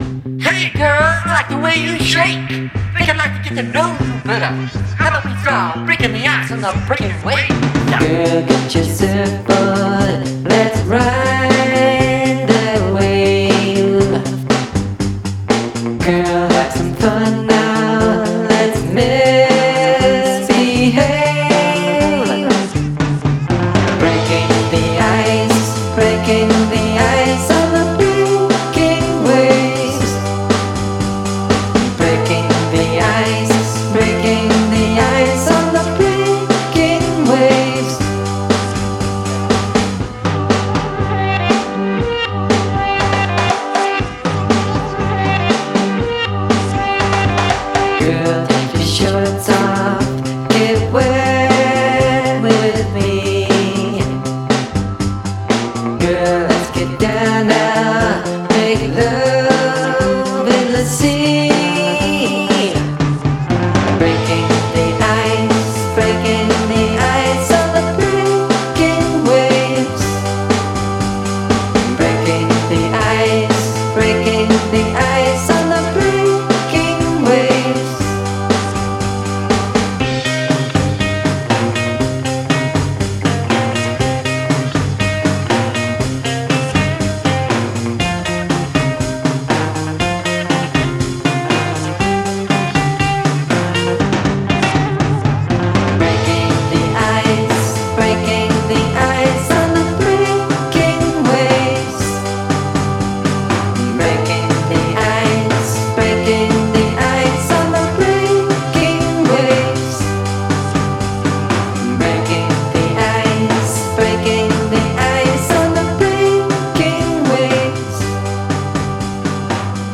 Significant Use of Shouting